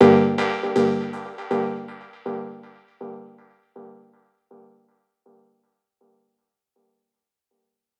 ff_ddt_synth_terrier_Gmin.wav